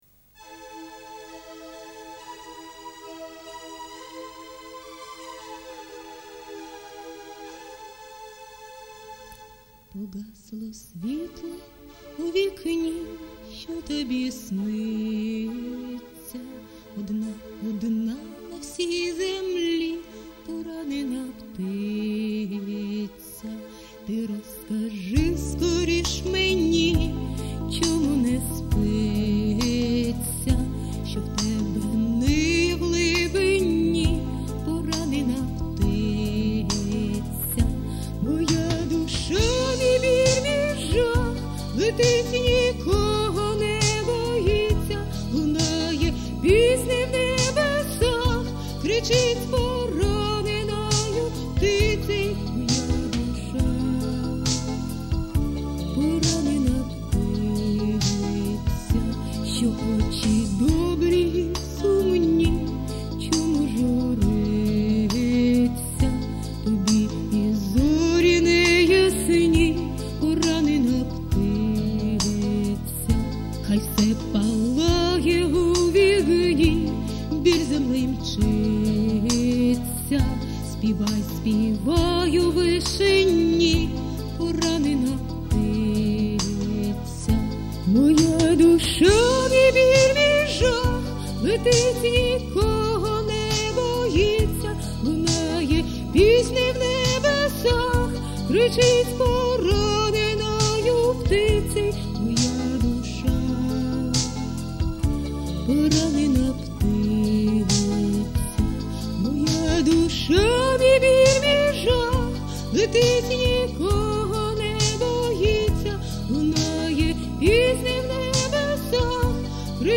...голос вражає..відчувається як він йде з грудей. 12